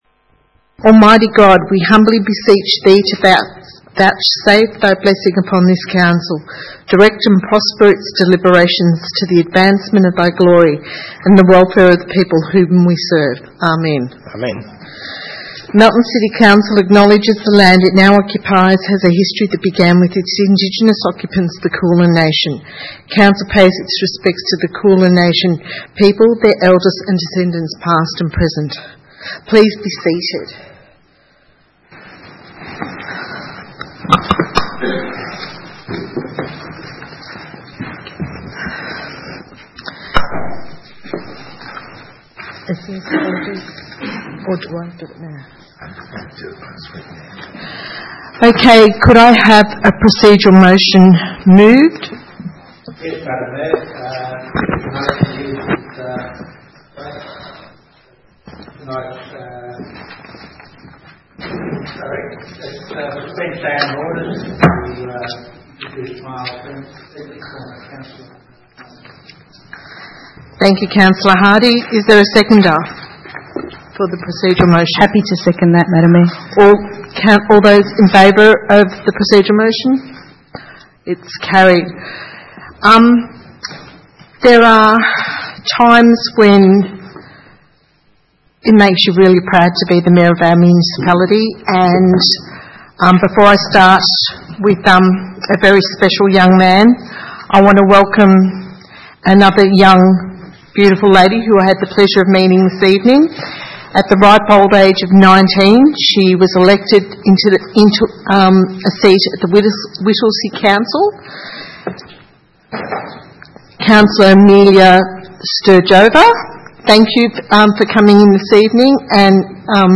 29 May 2017 - Ordinary meeting